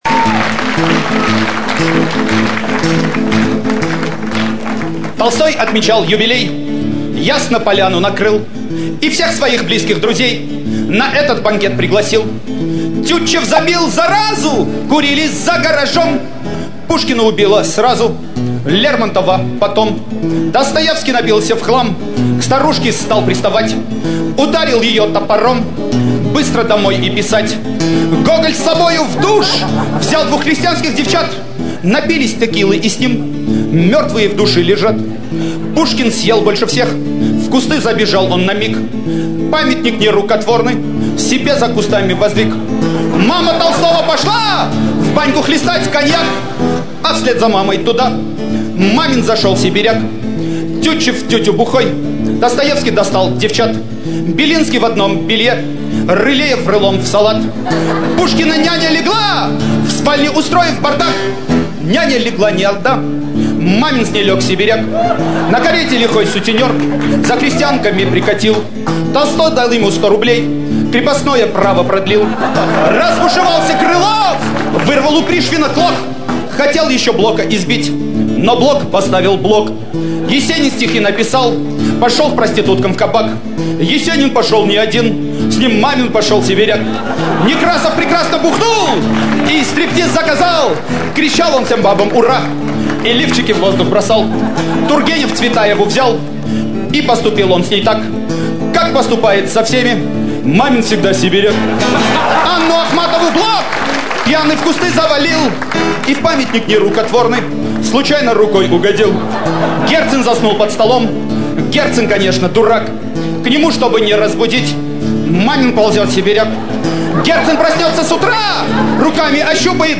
А вот песенка